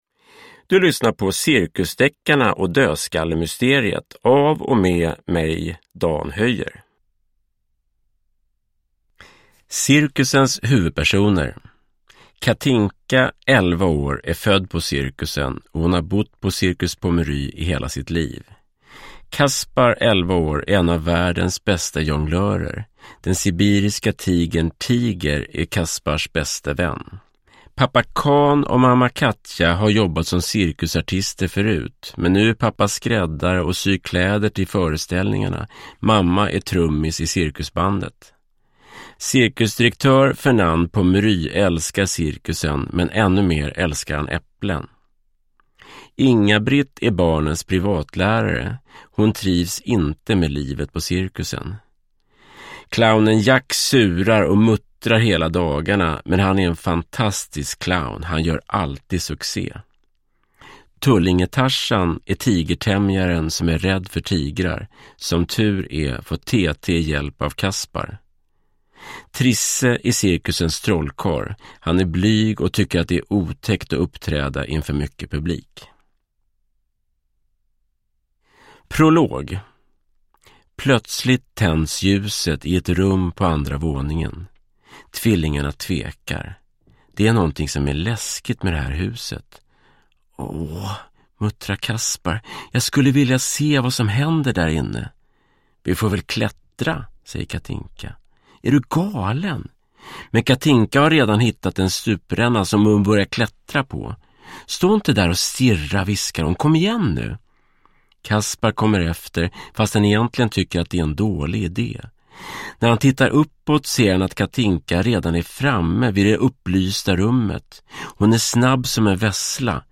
Cirkusdeckarna och dödskallemysteriet – Ljudbok – Laddas ner